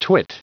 Prononciation du mot twit en anglais (fichier audio)
Prononciation du mot : twit